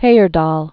(hāər-däl, hī-), Thor 1914-2002.